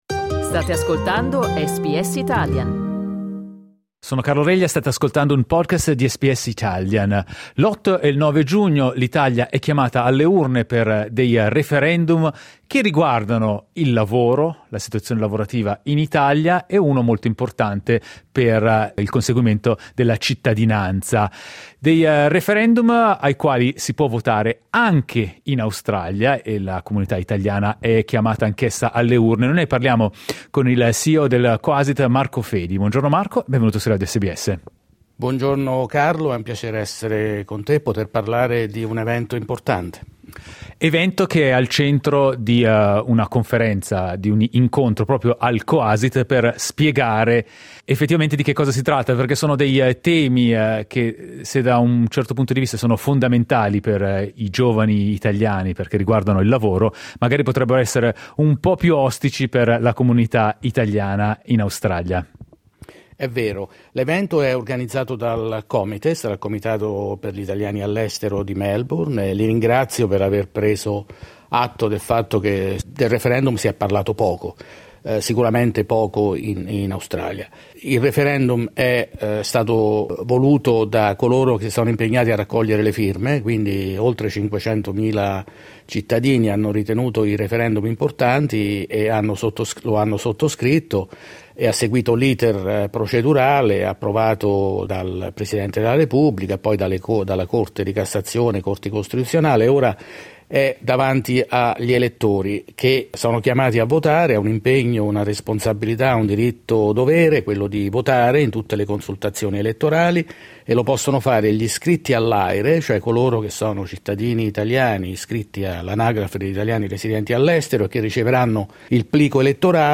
Ascolta la sua intervista cliccando sul tasto "play" in alto Ascolta SBS Italian tutti i giorni, dalle 8am alle 10am.